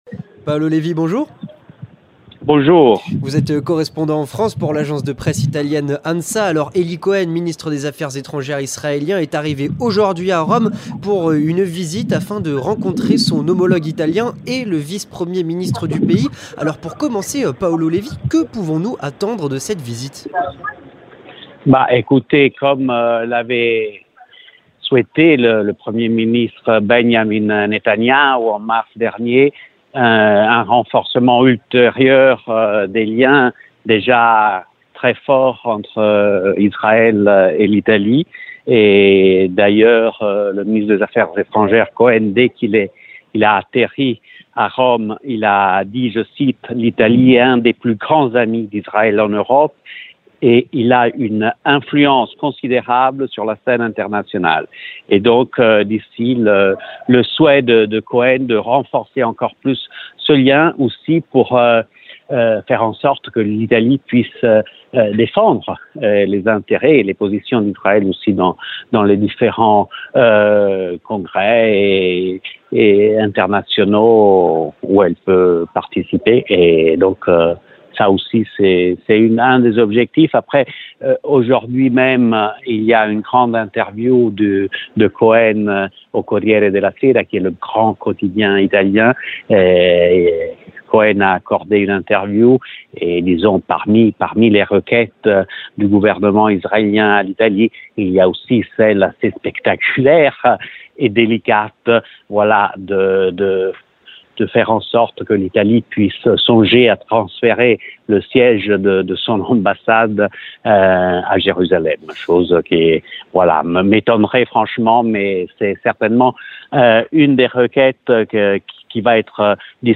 Entretien du 18h - Visite d’Eli Cohen en Italie et au Vatican